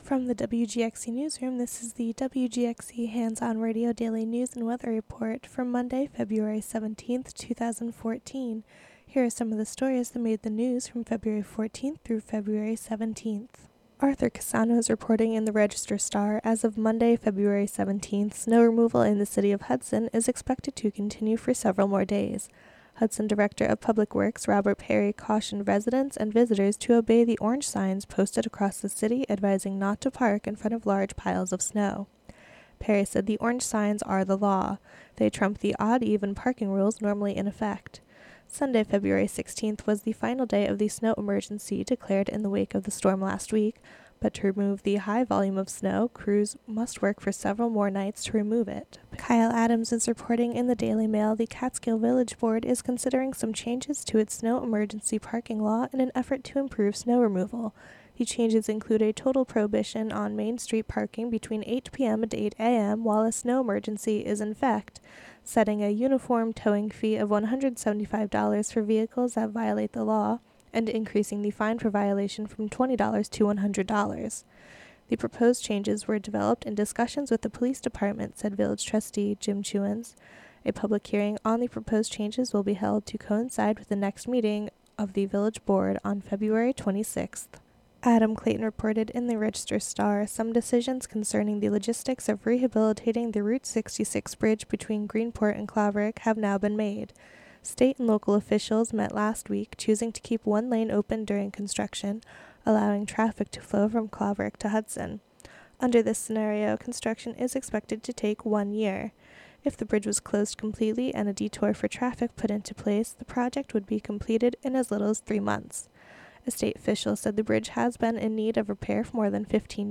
Local news and weather for Monday, February 17, 2014.